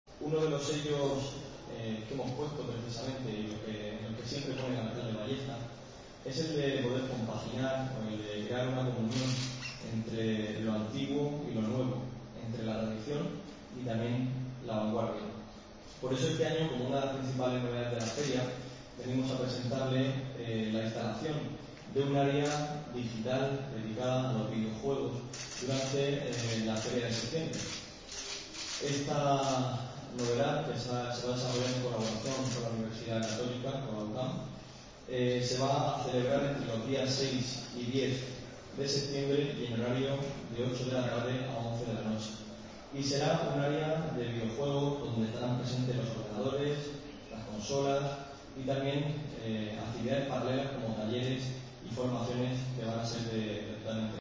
Diego Avilés, concejal de Cultura e Identidad